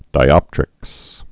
(dī-ŏptrĭks)